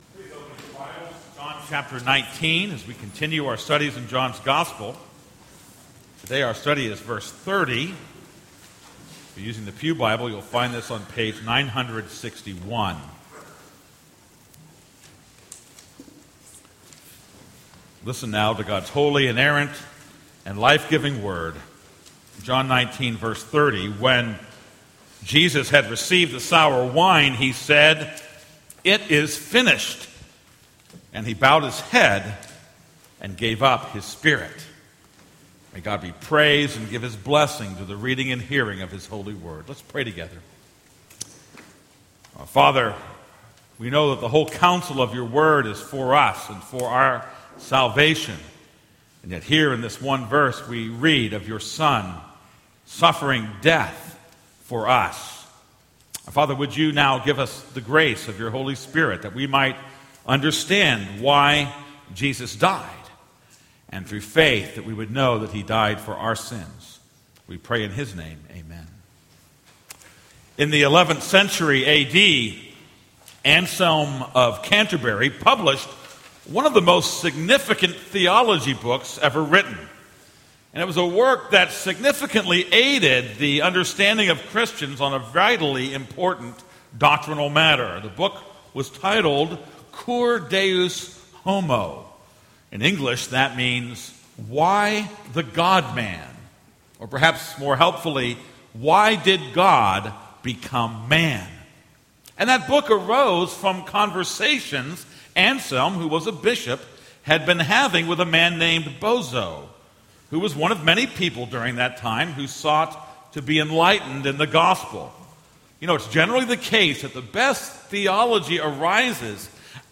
This is a sermon on John 19:28-30.